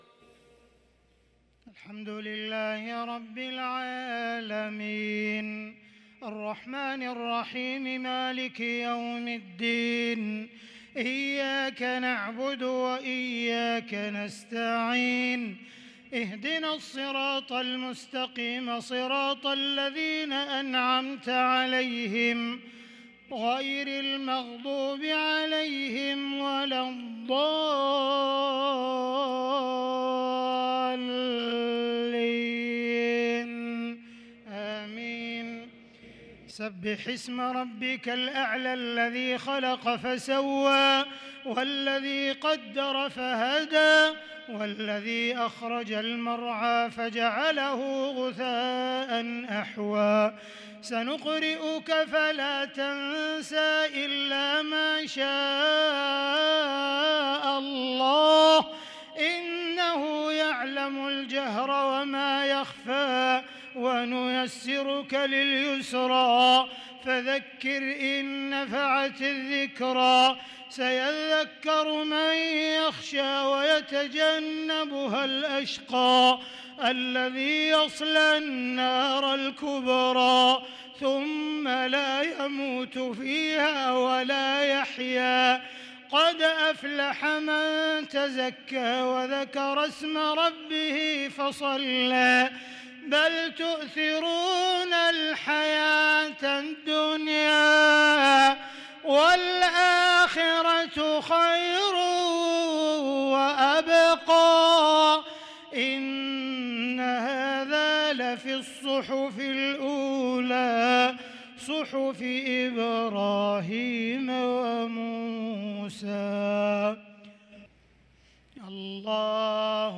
صلاة الجمعة 13 صفر 1444هـ سورتي الأعلى و الغاشية | Jumu’ah prayer from Surah Al-a’ala & Al-Ghashiya 9-9-2022 > 1444 🕋 > الفروض - تلاوات الحرمين